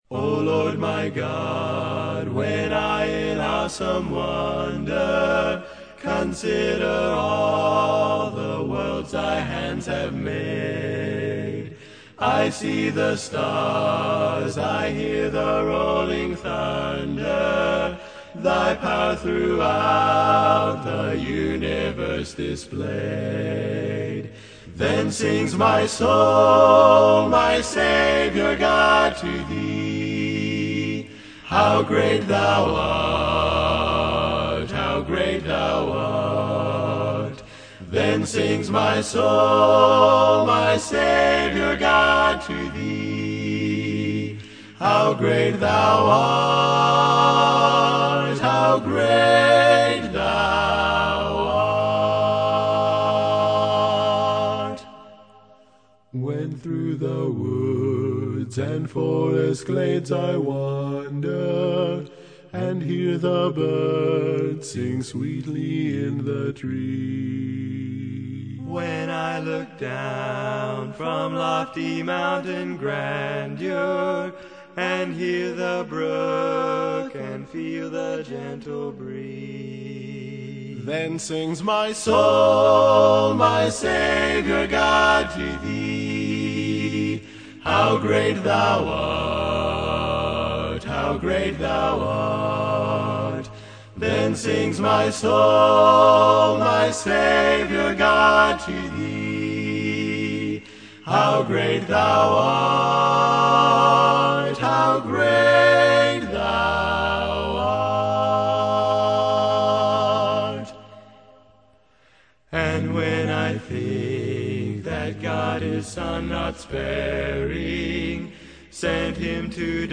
Male quartet